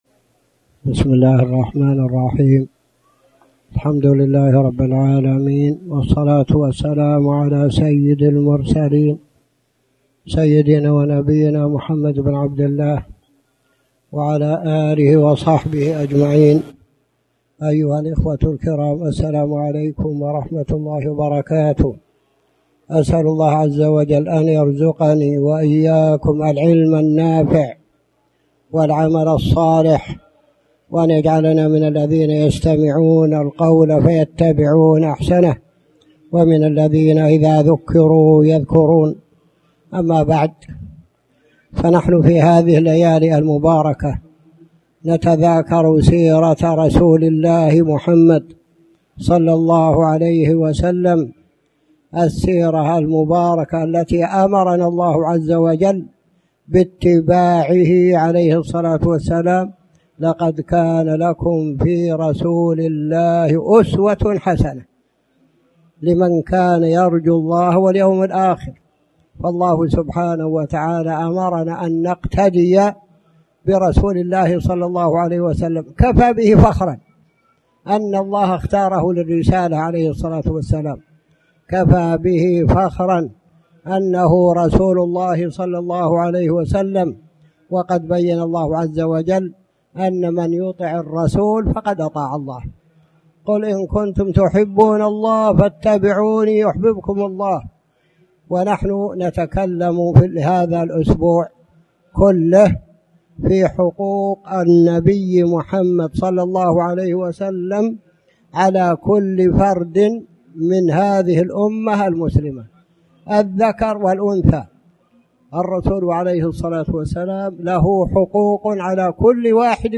تاريخ النشر ١٨ محرم ١٤٣٩ هـ المكان: المسجد الحرام الشيخ